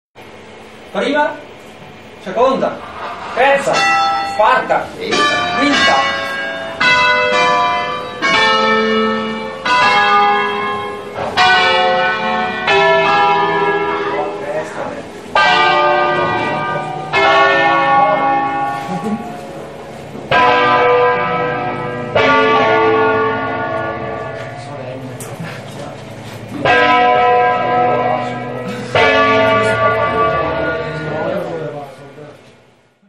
Serata ricca di suono di campane a Cologne in occasione dei 500 anni del campanile della Parrocchiale.
La Federazione Campanari Bergamaschi, che conta anche su associati bresciani, ha offerto l’ascolto di suonate d’allegrezza (o a tastiera) della Valle Seriana e Valle Gandino, ed esempi di suono a distesa con scale ‘alla bergamasca’, che si differenziano dal suono bresciano (fatto di suoni alternati tra le campane pari e dispari presenti sul campanile) per il fatto di andare ‘
al botto‘, creando tra campane dispari (1,3,5,7) e pari (2,4,6,8) degli accordi.